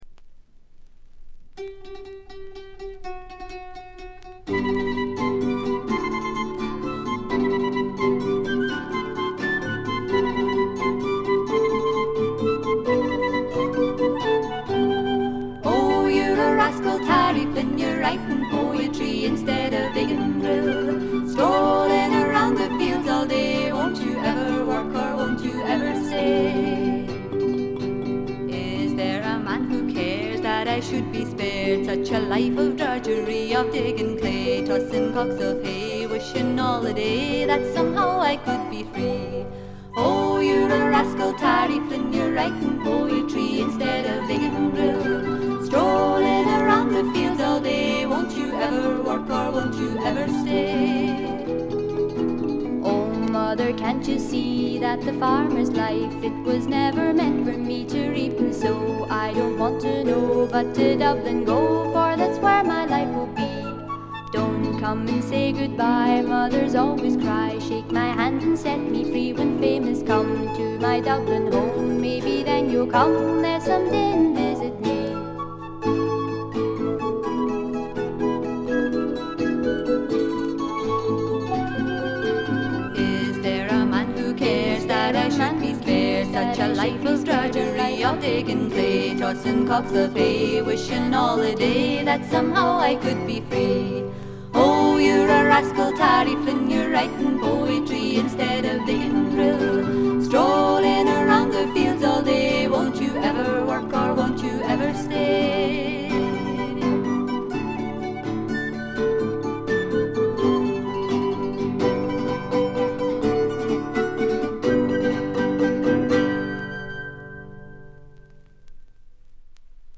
軽微なチリプチやバックグラウンドノイズ、散発的なプツ音少々。
内容はまさしく天使の歌声を純粋に楽しめる全14曲です。
アレンジはあくまでもフォークであってギターのアルペジオ主体の控え目なものです。
試聴曲は現品からの取り込み音源です。